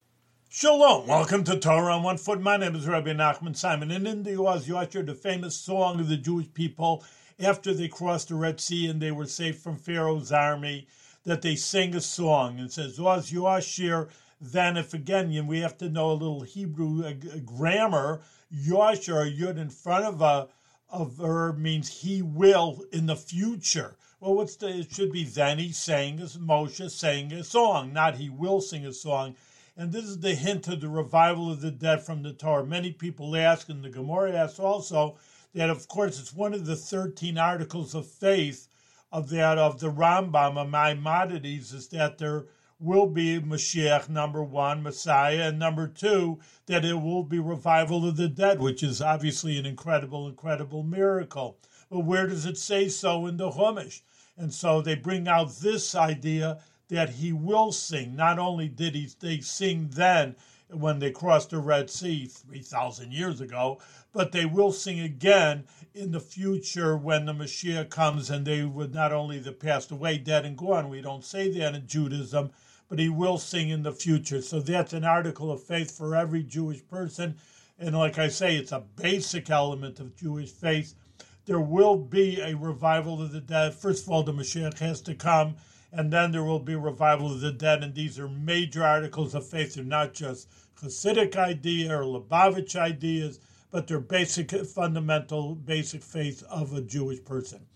One-minute audio lessons on special points from weekly Torah readings in the Book of Exodus.